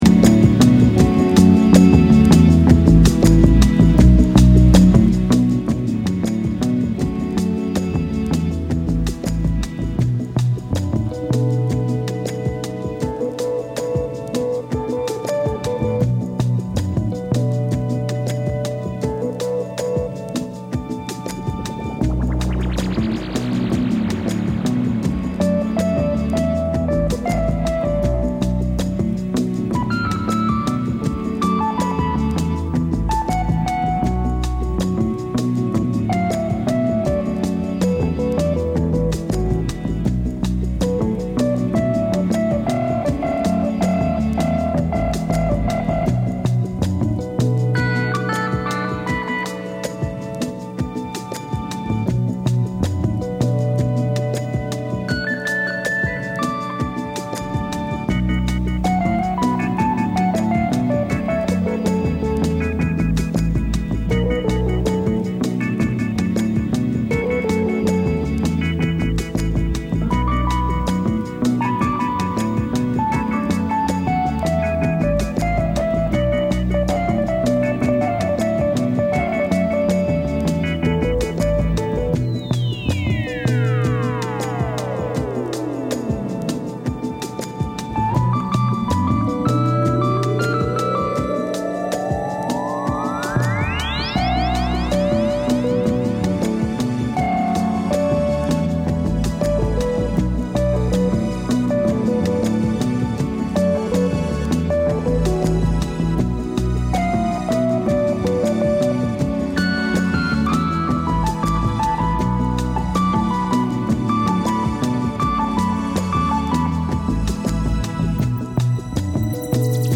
a frequent DJ collaborator